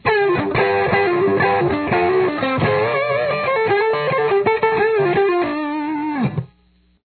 Solo Part 2